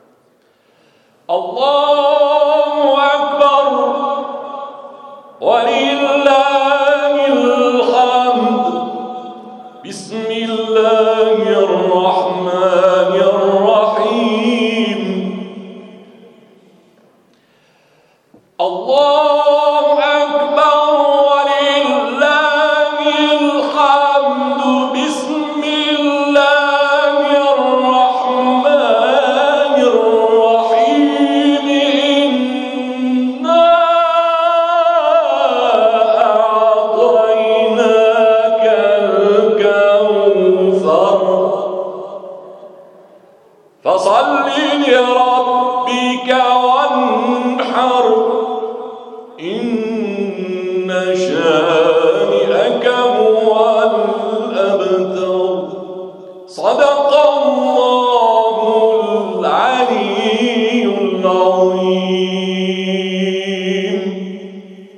سوره کوثر ، پویش ملی تلاوت سوره کوثر